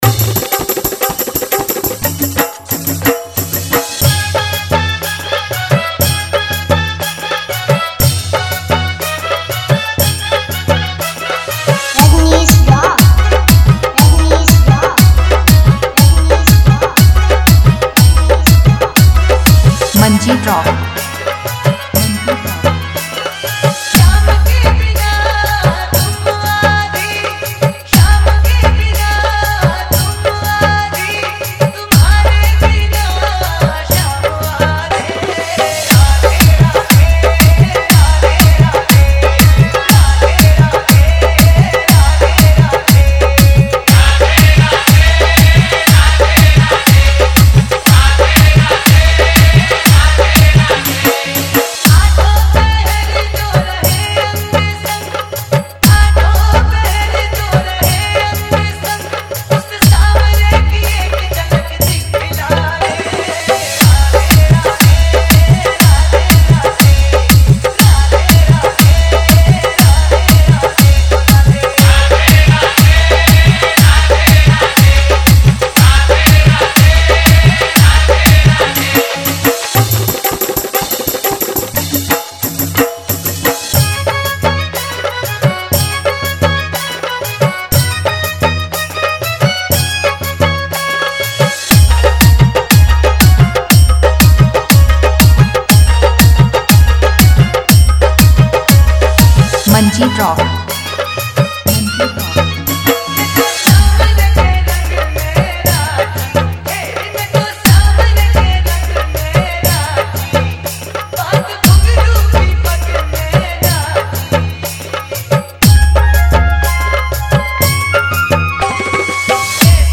Bhakti Dj Remix Song Mp3, Krishna Bhajan Dj Remix Song
Radhe Radhe Dance Dj Song